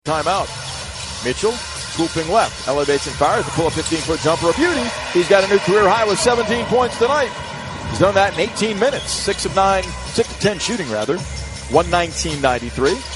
Thunder PBP 12-30 Mitchell.mp3